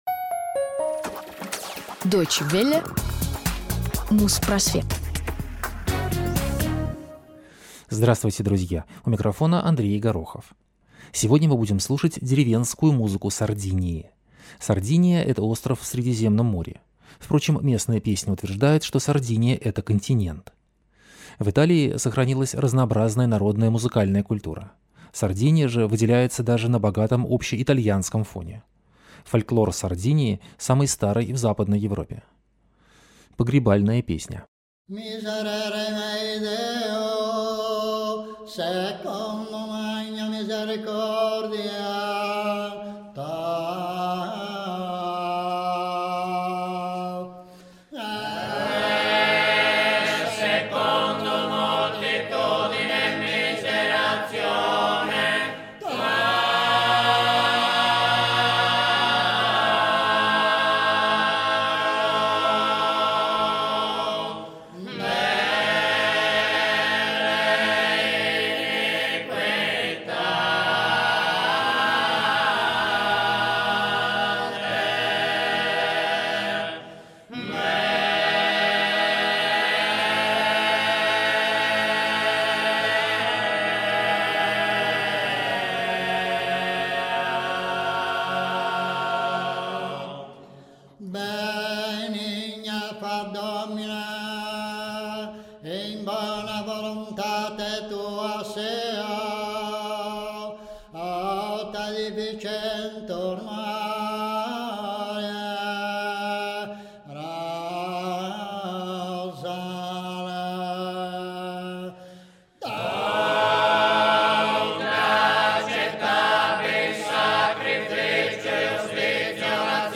Музпросвет 337 от 24 января 2009 года - Традиционная музыка Сардинии | Радиоархив